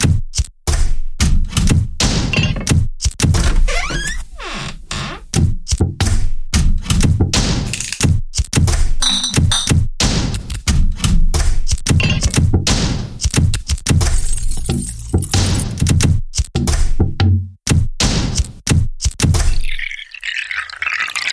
Tag: 90 bpm Electronic Loops Synth Loops 3.59 MB wav Key : Unknown